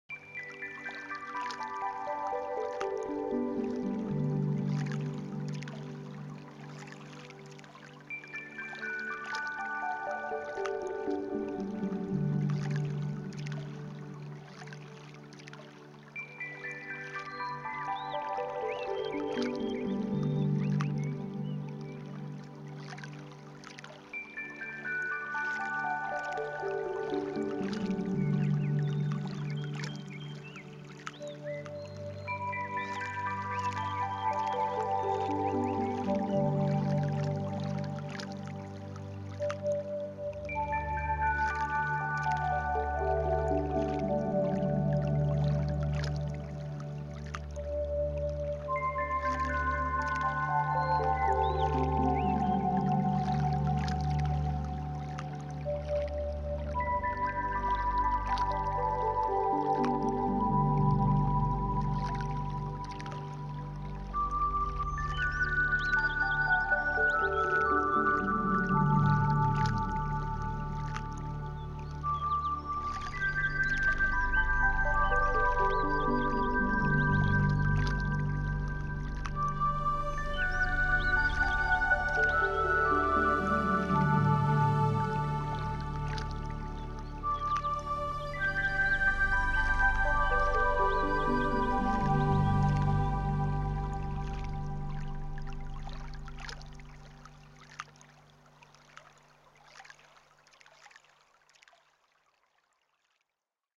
very relaxing, with nature-sounds
the sound of a brook, a bird etc.